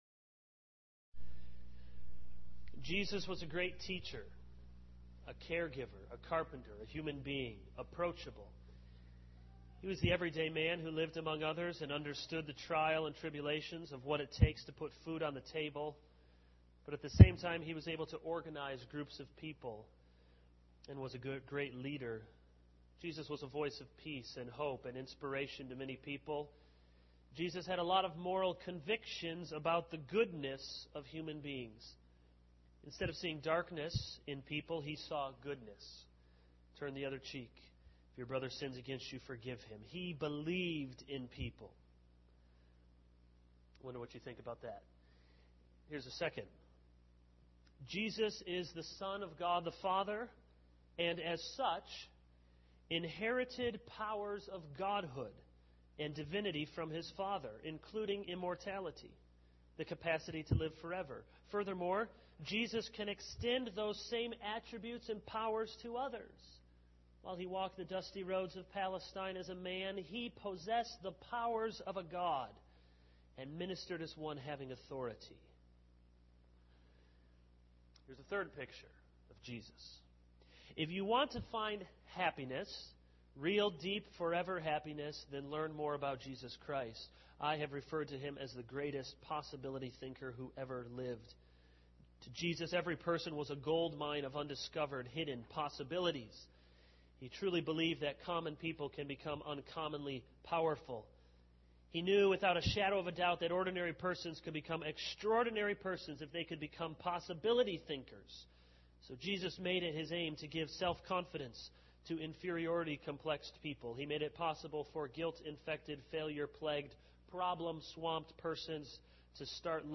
This is a sermon on 2 Corinthians 11:1-15.